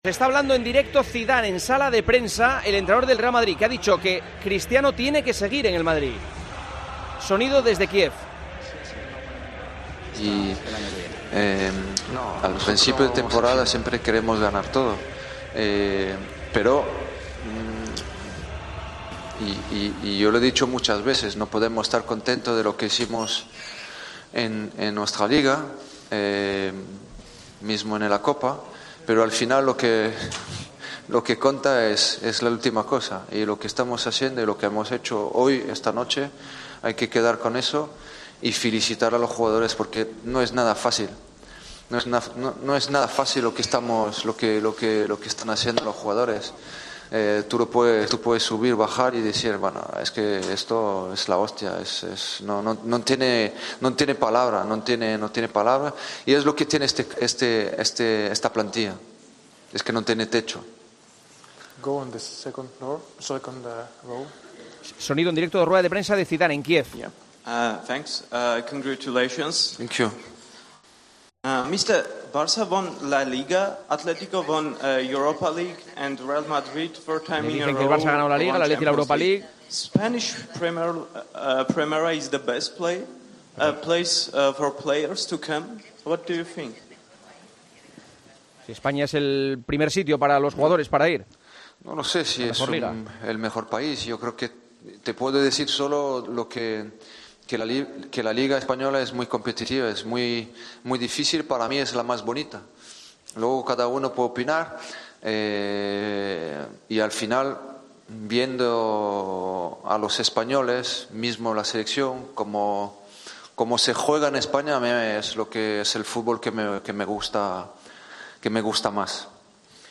Zidane abogó en rueda de prensa por que Cristiano Ronaldo se quede: "Cristiano tiene que seguir en el Madrid.